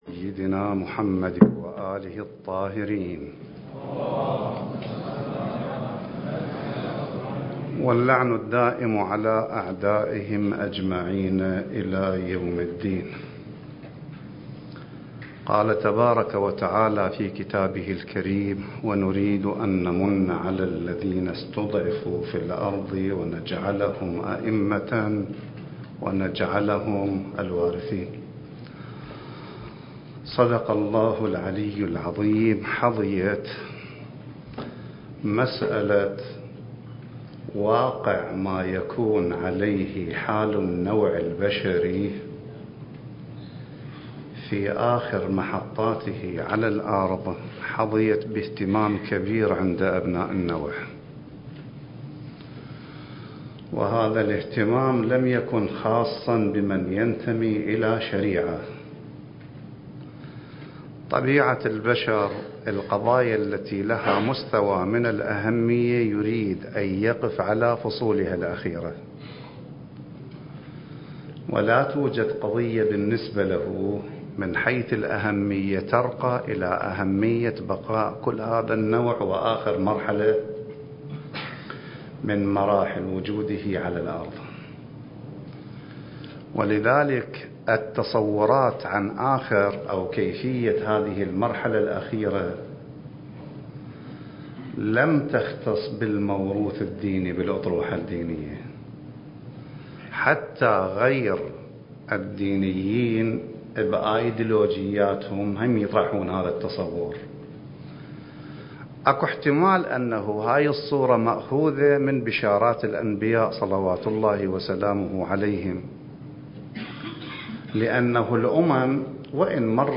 الدورة المهدوية الأولى المكثفة (المحاضرة الثالثة)